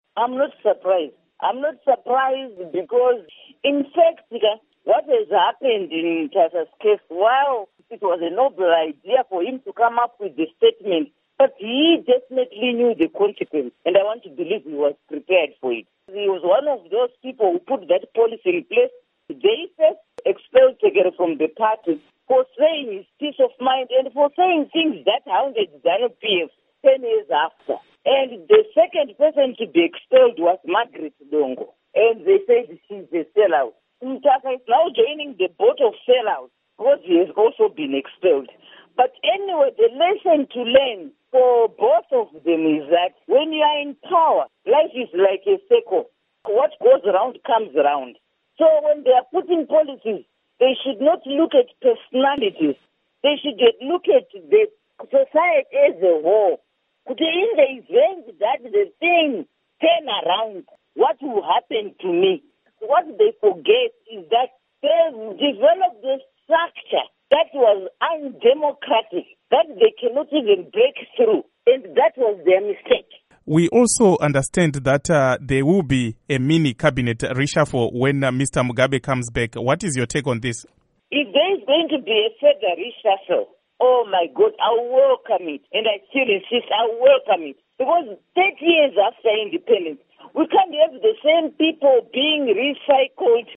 Interview With Magaret Dongo on Zanu PF Squabbles